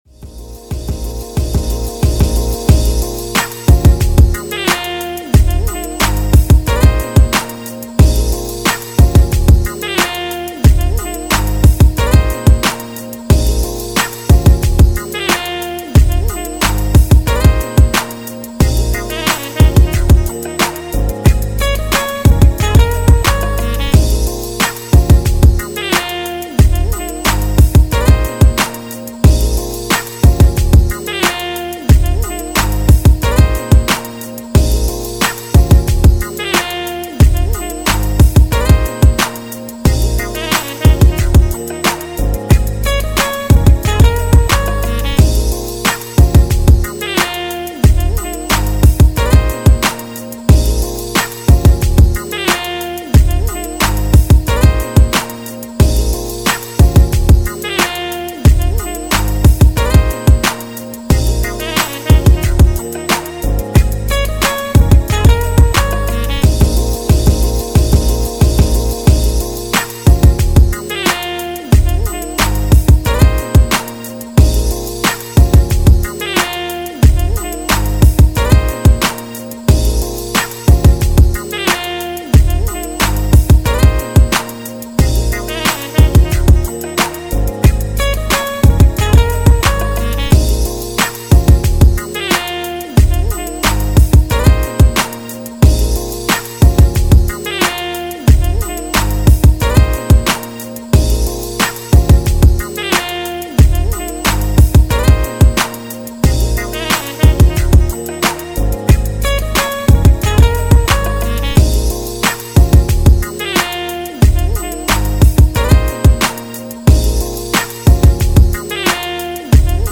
Smooth and sultry Compilation of beats
a selection of Funk, Soul and Jazz.